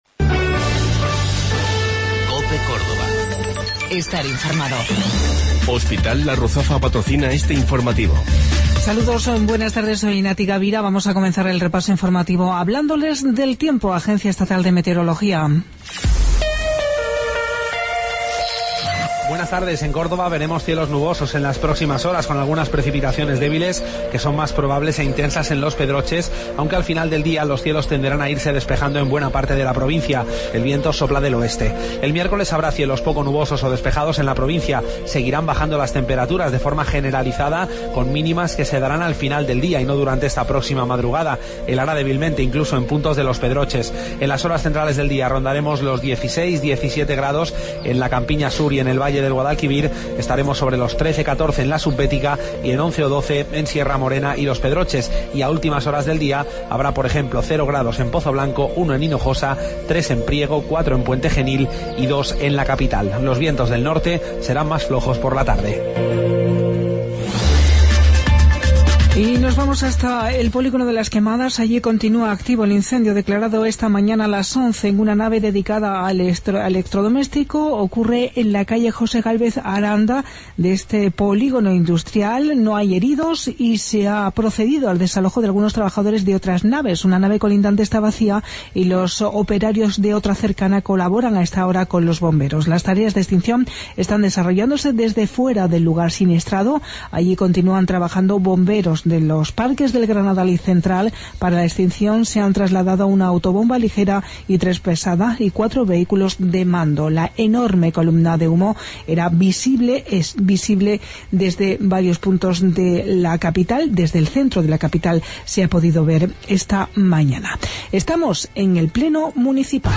Mediodía Cope. Informativo Local, 7 de Febrero 2017